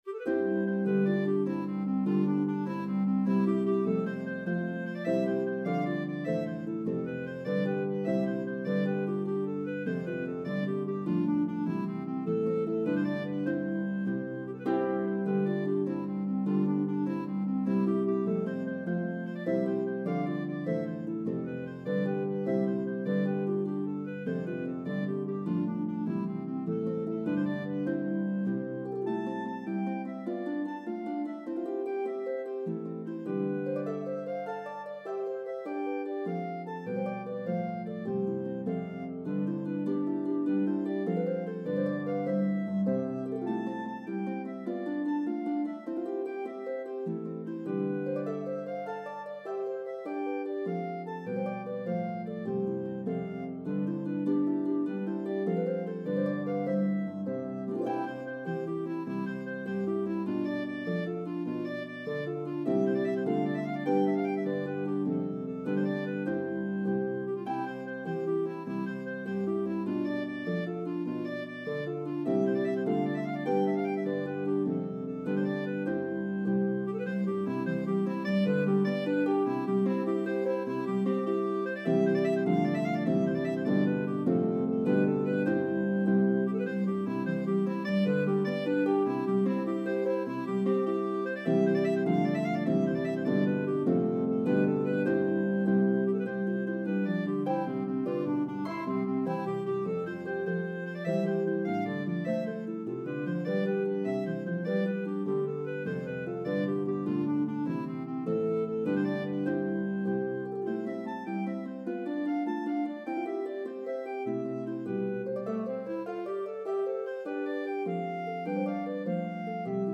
A spirited Irish Jig
The Harp part is playable on either Lever or Pedal Harps.